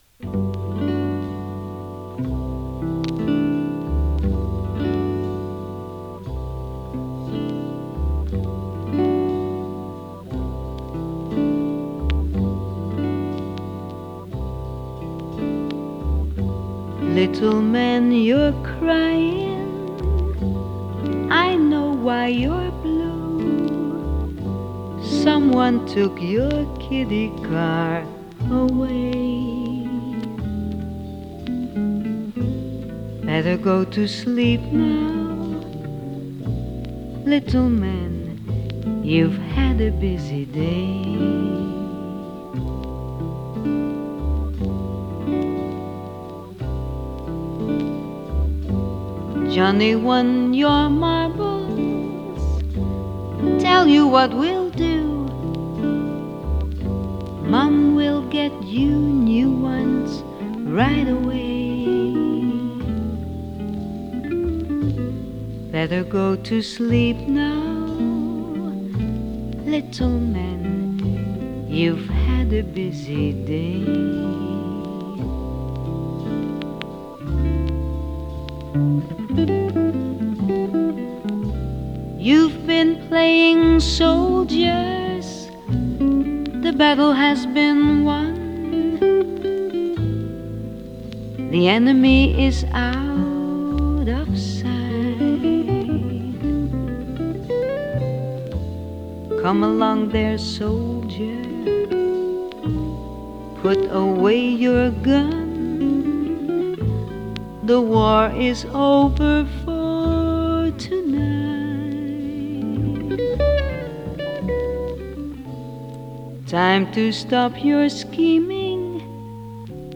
ラウンジ ジャズボーカル
オランダを代表する女性ジャズ・シンガー。